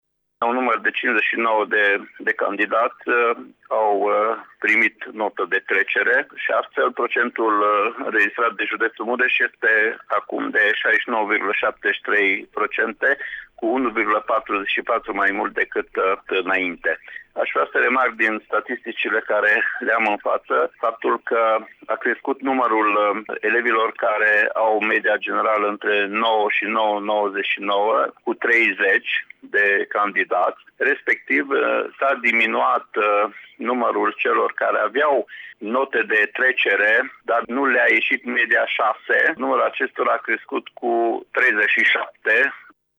După rezolvarea acestora, promovabilitatea pe judeţ a crescut de la 68,3% la 69,7%, ne-a declarat inspectorul școlar general al județului Mureș, Ştefan Someşan: